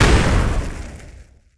fireimpact04.wav